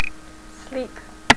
sleep1.wav